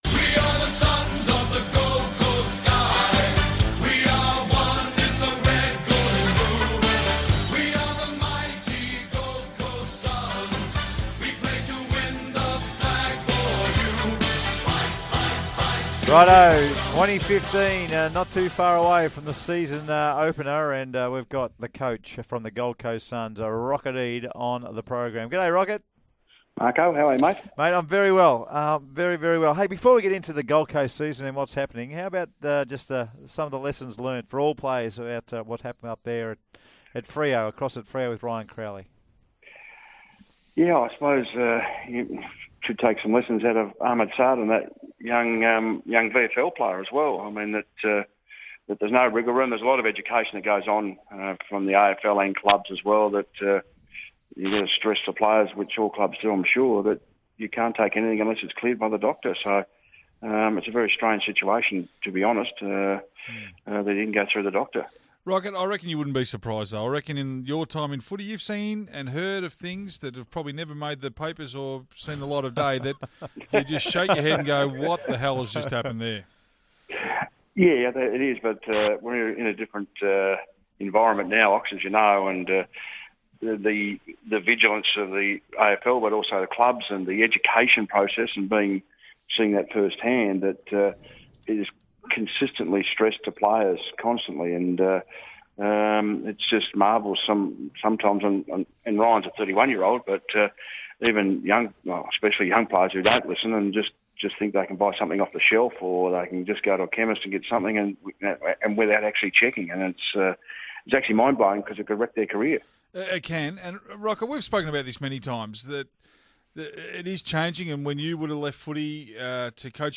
GC SUNS coach Rodney Eade speaks with SEN's The Run Home previewing the 2015 AFL season.